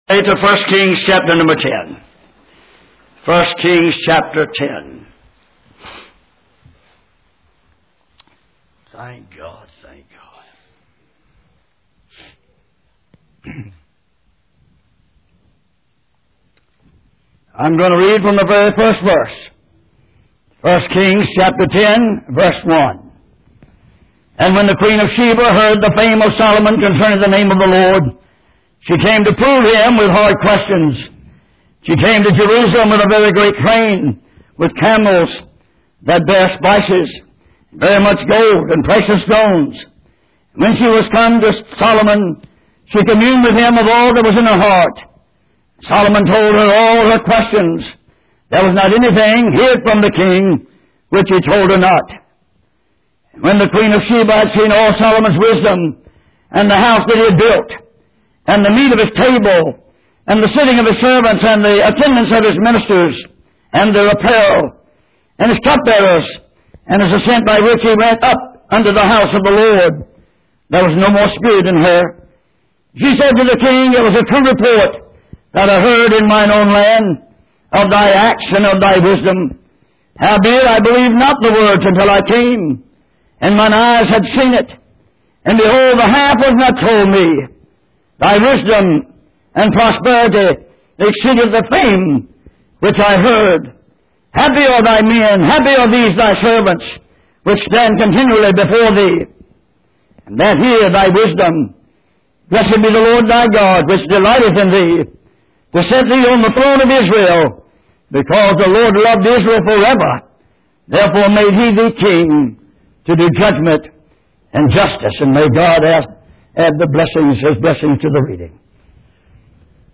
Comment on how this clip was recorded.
Passage: 1 Kings 10:1-9 Service Type: Sunday Morning